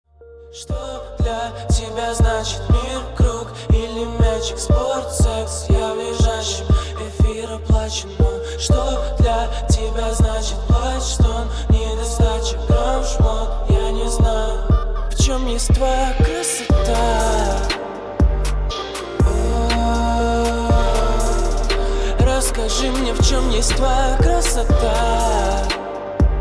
• Качество: 320, Stereo
мужской вокал
лирика
русский рэп
мелодичные
спокойные